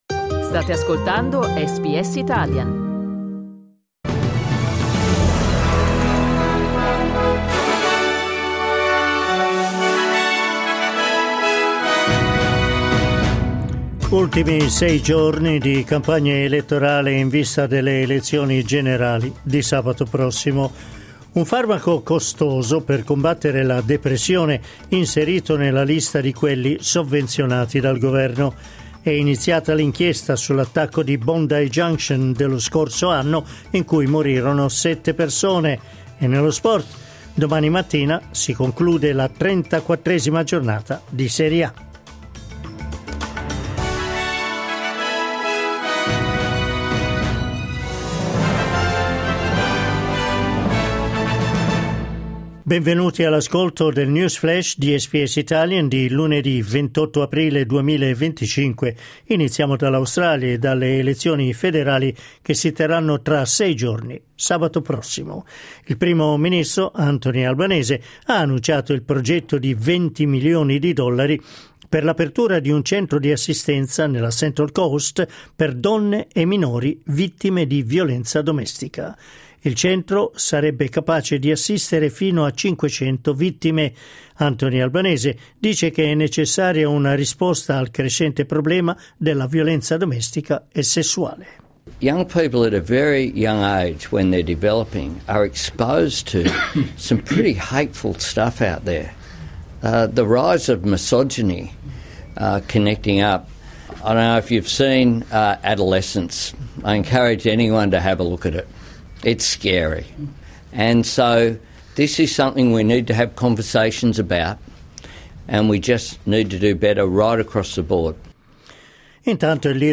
News flash lunedì 28 aprile 2025